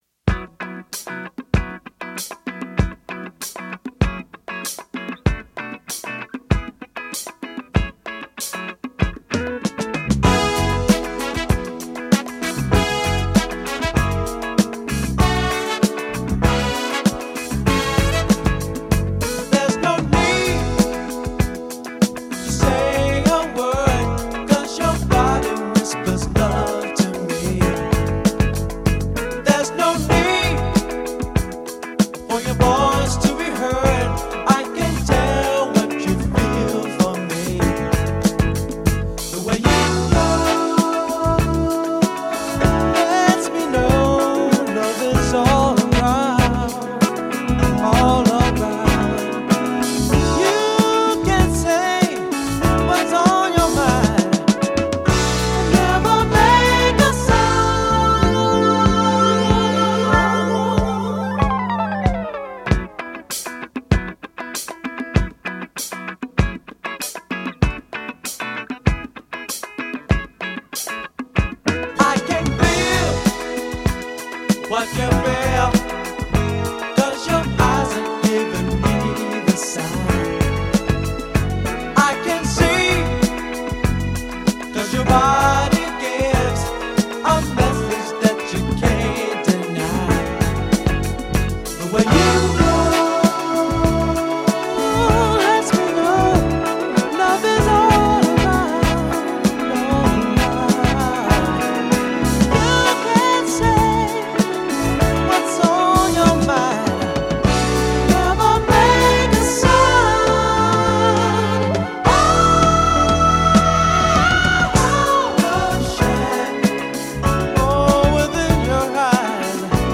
Another slice of smooth funk
funk/soul outfit